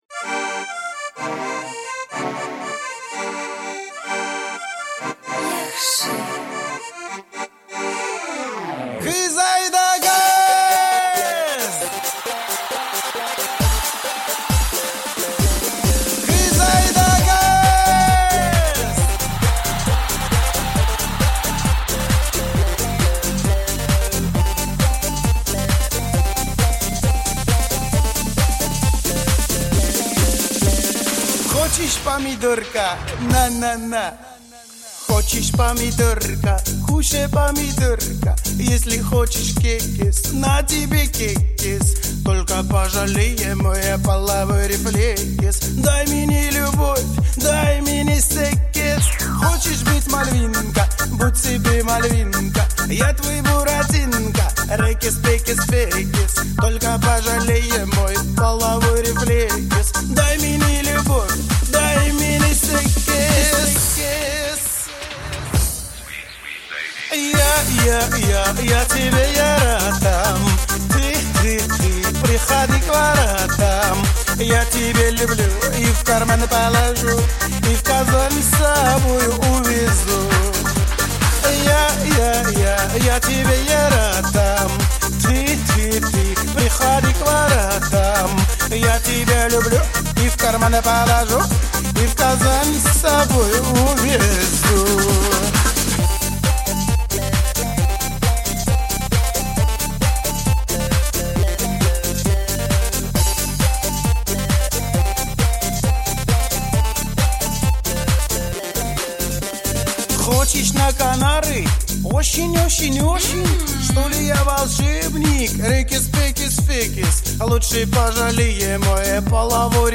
татарская_шуточная_-_Кушай_помидорка